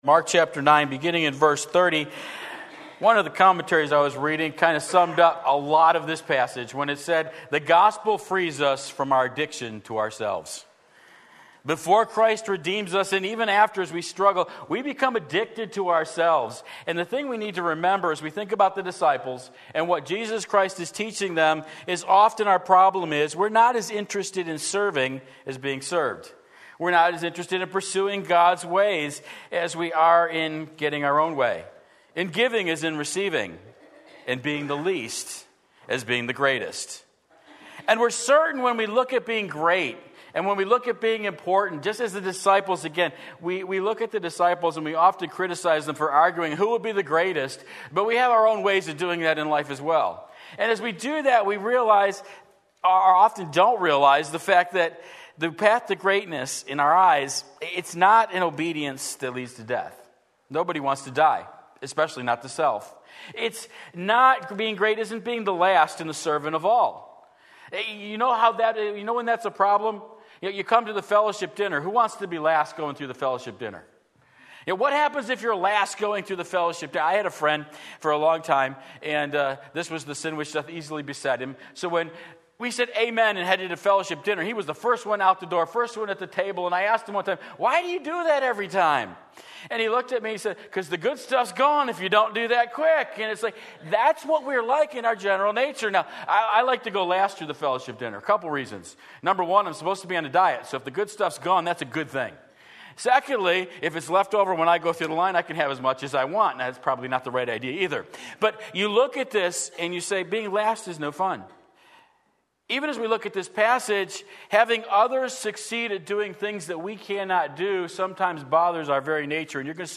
Sermon Link
The Road of Discipleship Mark 9:30-50 Sunday Morning Service